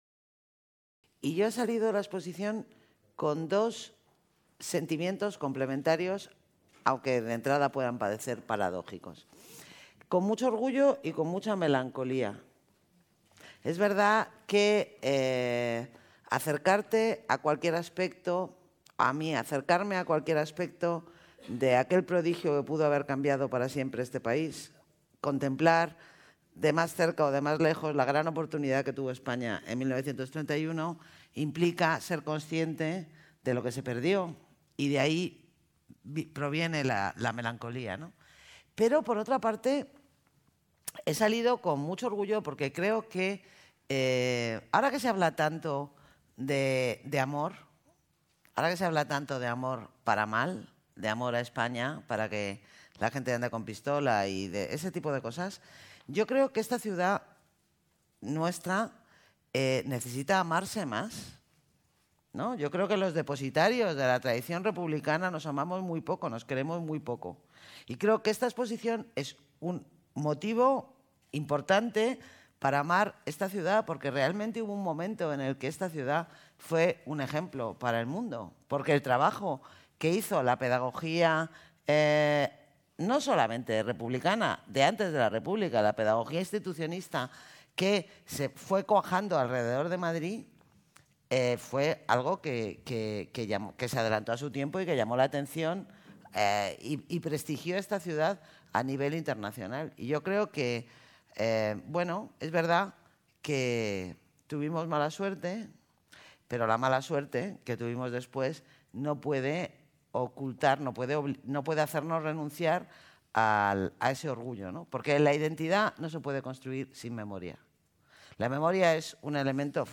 Coloquio entre Manuela Carmena y Almudena Grandes